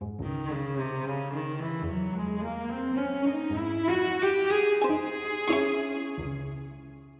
A trivial piece but written with something commercial in mind. A small ensemble: 2 violins, 2 cellos, alto flute, oboe, horn, clarinet and bass clarinet.